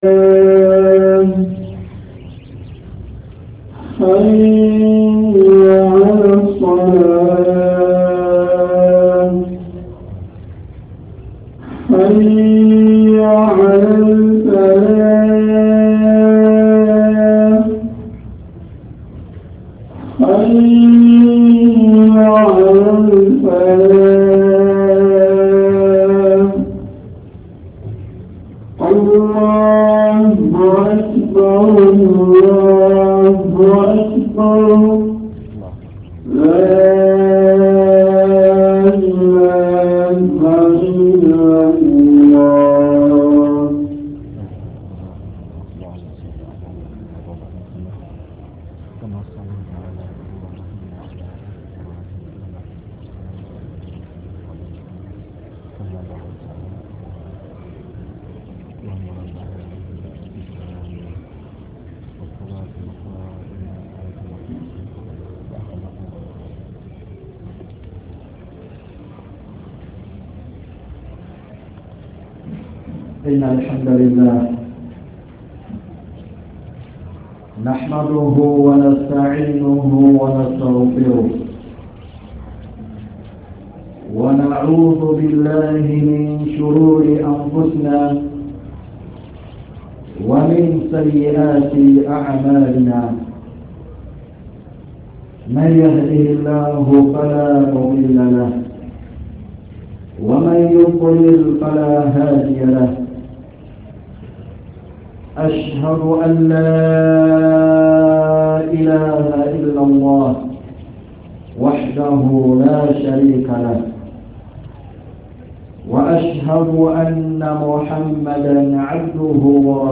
JUMMA KHUTUB
Masjid Taqwa kisenyi Download Audio